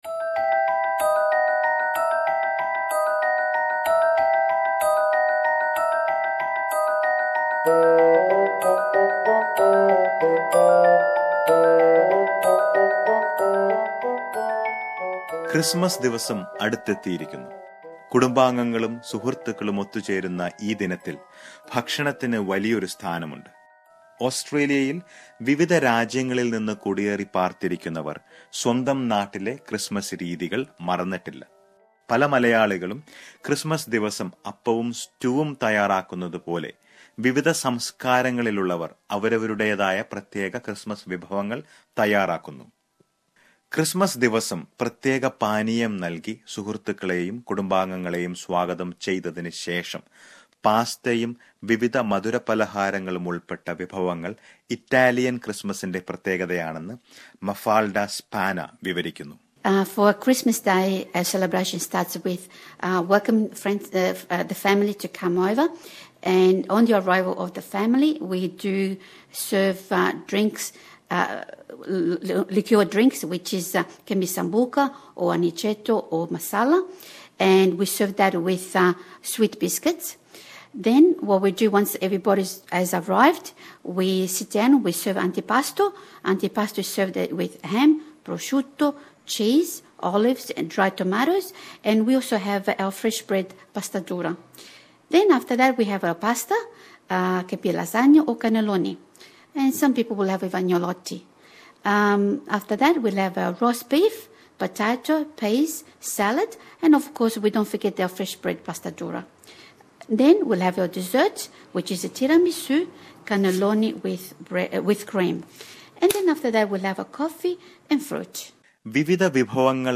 When Malayalees think of Appam and Stew as their favorite Christmas dish, European, African and Latin American countries boast of many delicious dishes. Let us listen to a report on some of them